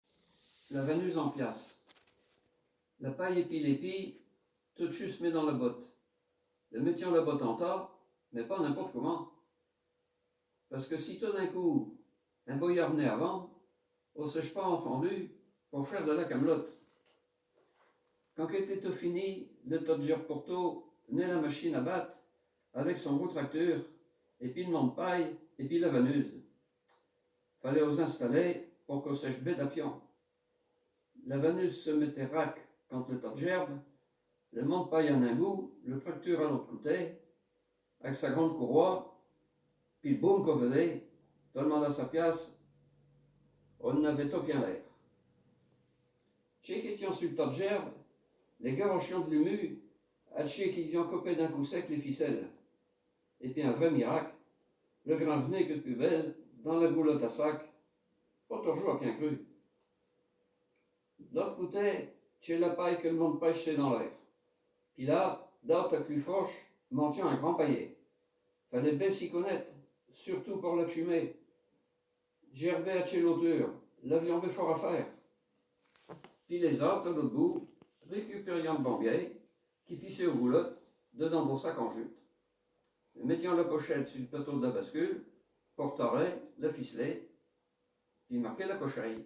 Genre poésie
Poésies en patois
Catégorie Récit